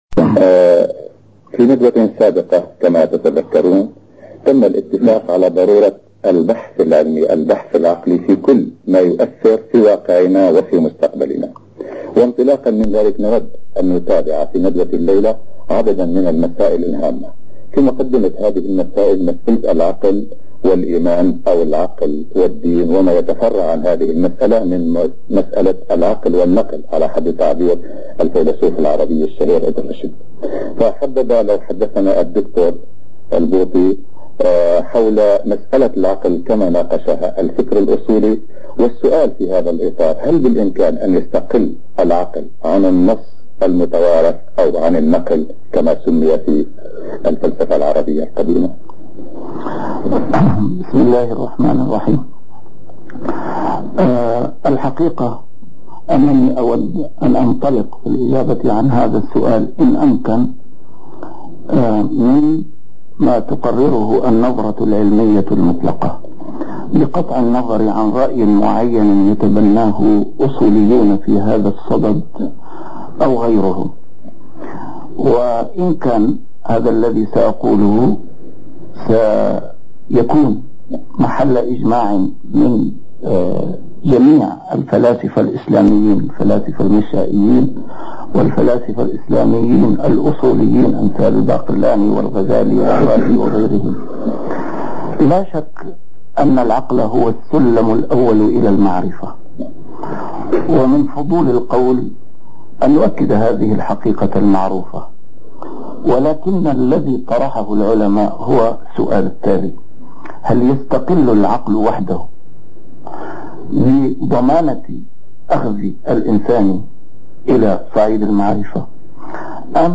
A MARTYR SCHOLAR: IMAM MUHAMMAD SAEED RAMADAN AL-BOUTI - الدروس العلمية - محاضرات متفرقة في مناسبات مختلفة - مناظرة مع الدكتور الطيب التيزيني حول التراث - الحلقة الثانية